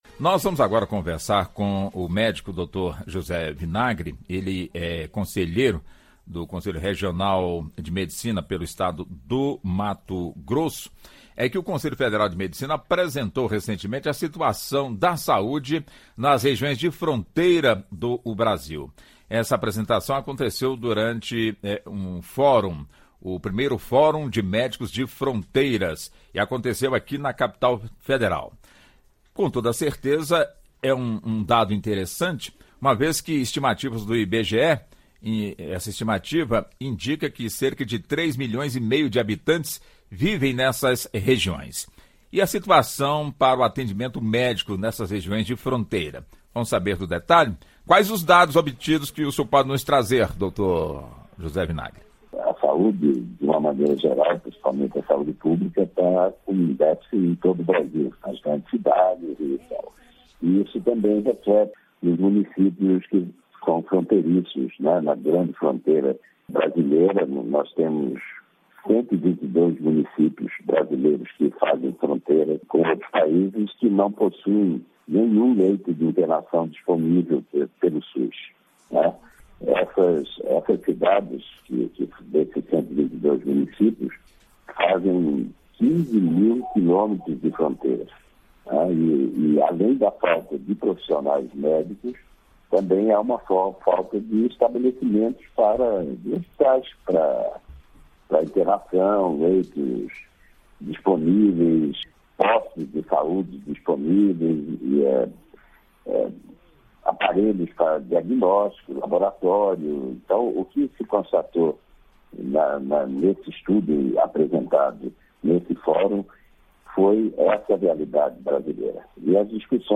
Entrevista: Entenda os problemas da saúde nas fronteiras do país